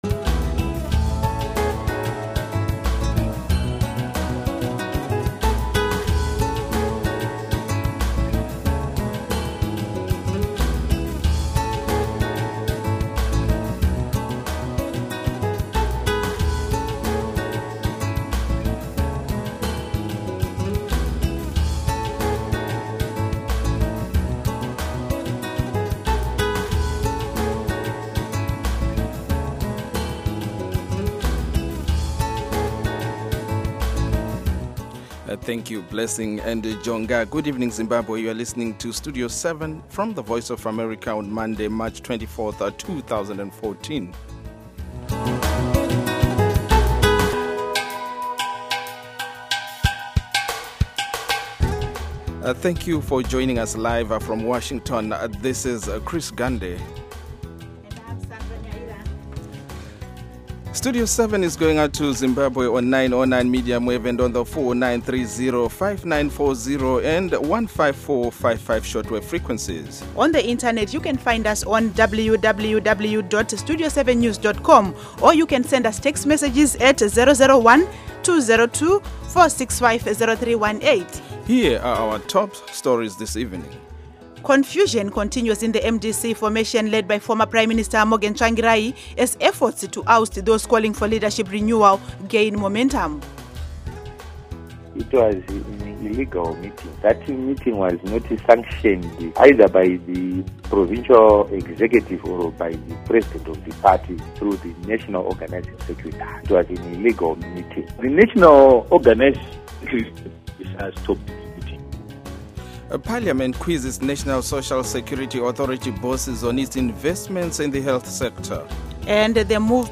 Studio 7 for Zimbabwe provides comprehensive and reliable radio news seven days a week on AM, shortwave and satellite Schedule: Monday-Friday, 7:00-9:00 p.m., Saturday-Sunday, 7:00-8:00 p.m., on Intelsat 10 repeats M-F 9-11 p.m. Local Time: 7-9 p.m. UTC Time: 1700-1900 Duration: Weekdays: 2 hours; Weekends: 1 hour Listen: MP3